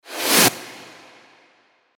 FX-634-WIPE
FX-634-WIPE.mp3